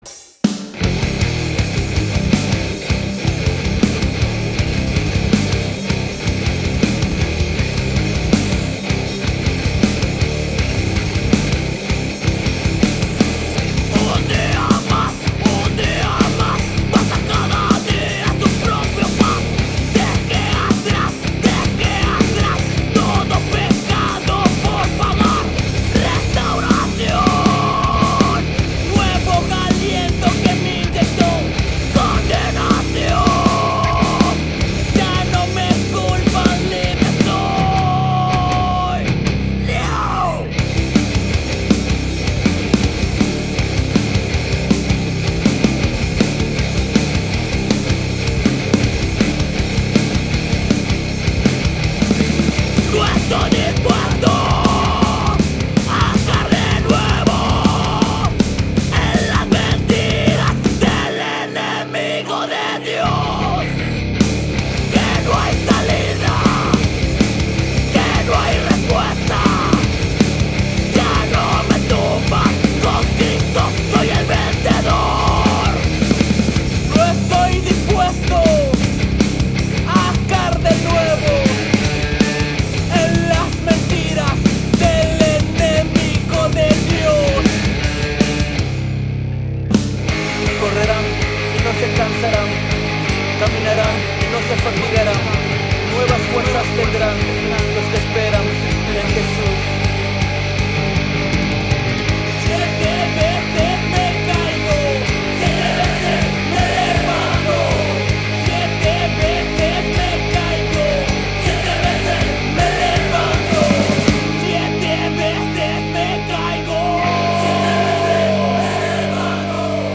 General, Mujeres Bellas, Música Cristiana
banda hardcore
voz
guitarrista
baterista
bajo
Hardcore.